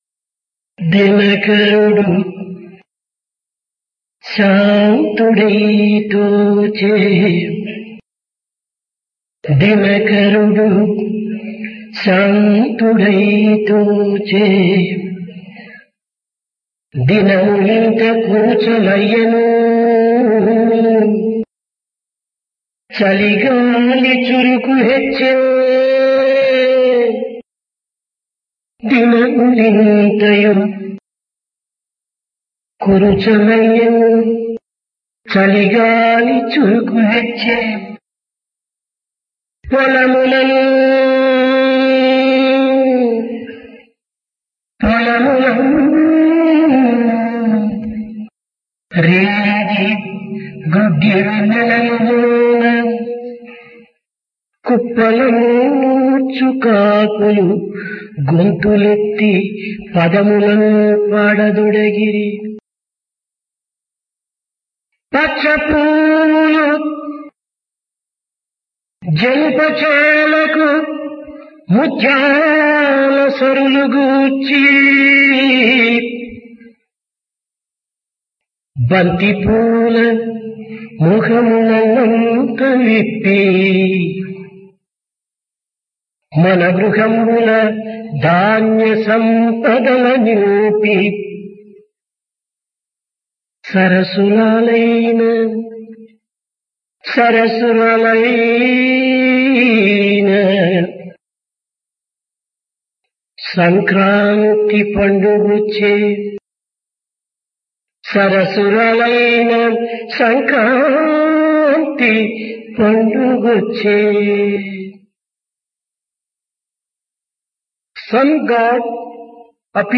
Discourse
Place Prasanthi Nilayam Occasion Sankranthi